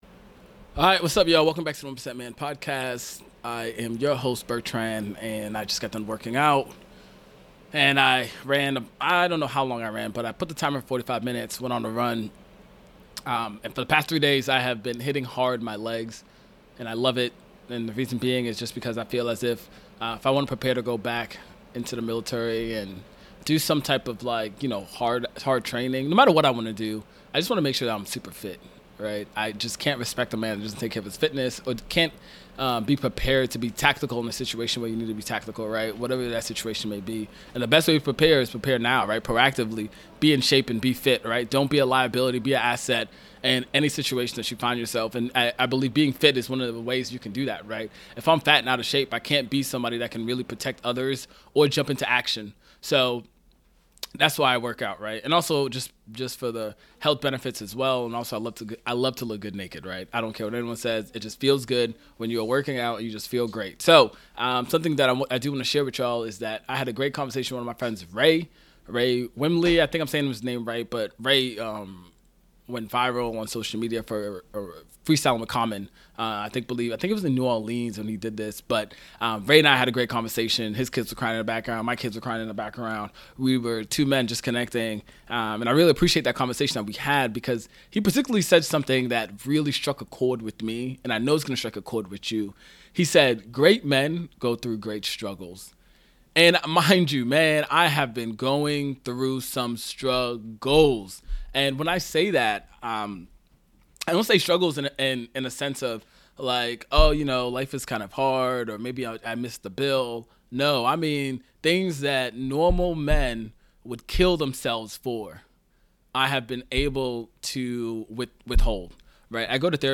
This is a real, raw transmission from a man walking through the fire—processing generational trauma, carrying business goals, fatherhood, past pain, and future vision—all without a blueprint.